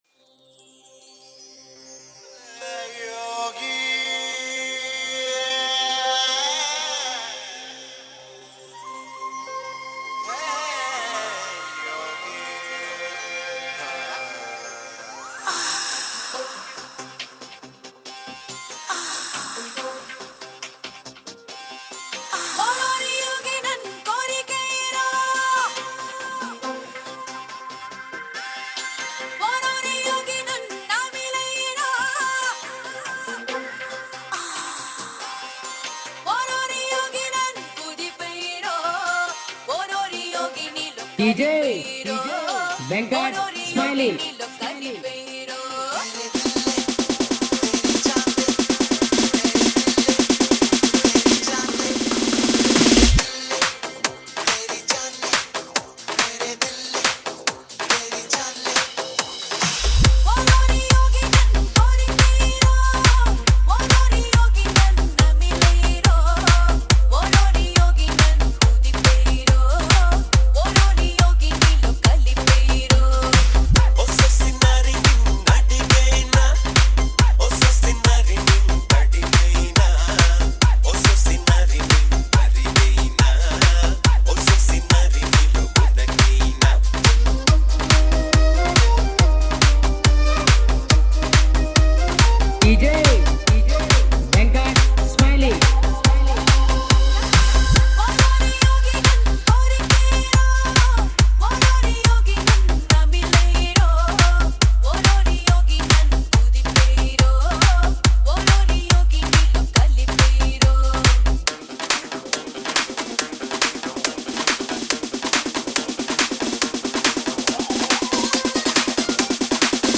Edm Bass Boosted Song Mix